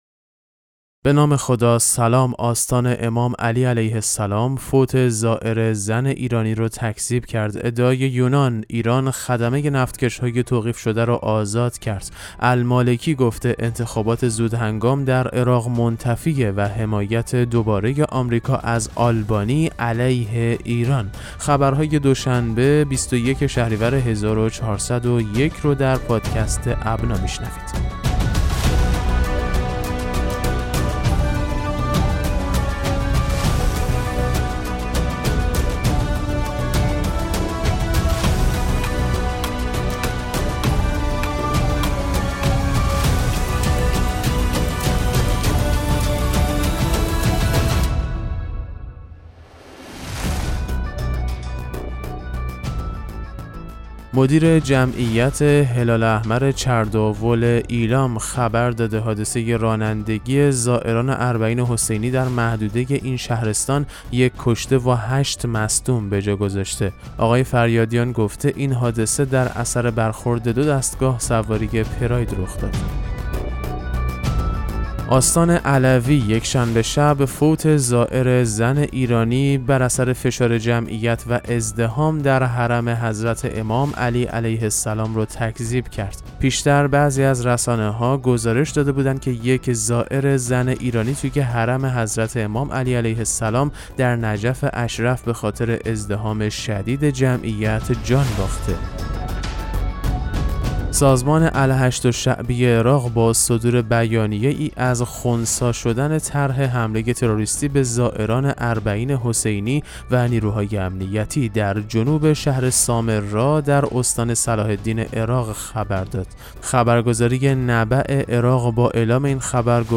پادکست مهم‌ترین اخبار ابنا فارسی ــ 21 شهریور 1401